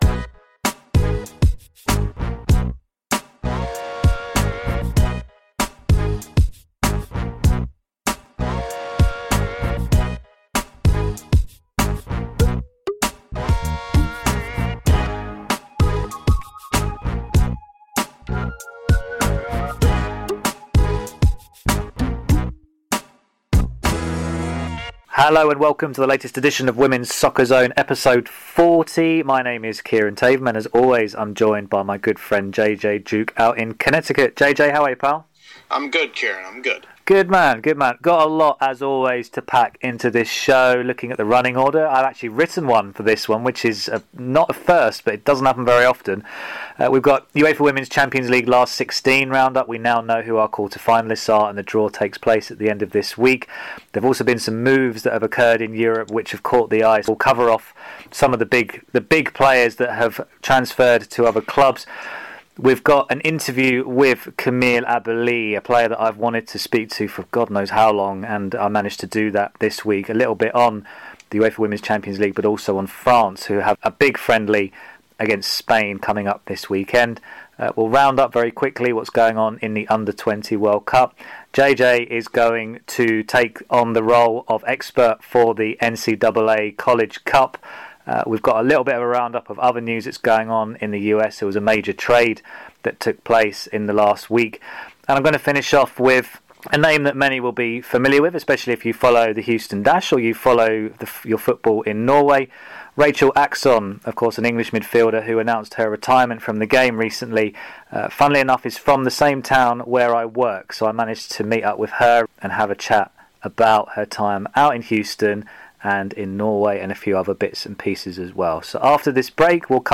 plus interviews with Lyon and France star Camille Abily